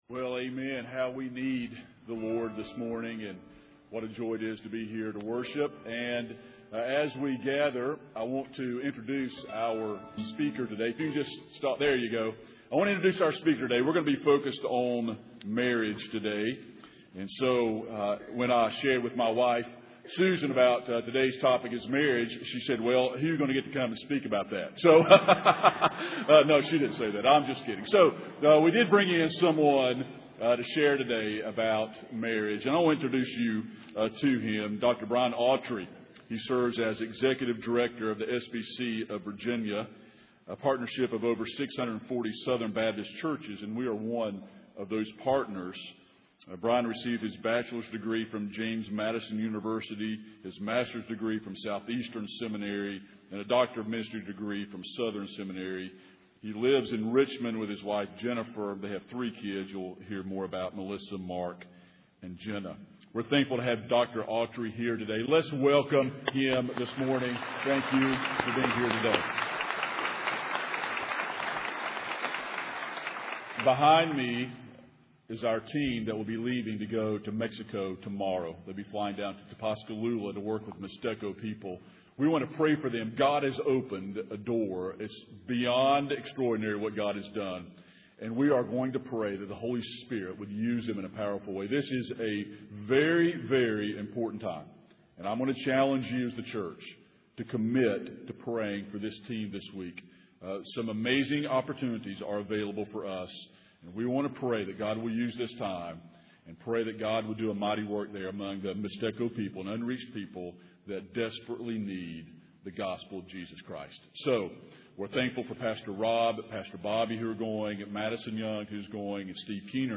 Standalone Sermons
These sermons were preached at SBCV churches throughout 2015, but are not a part of a series.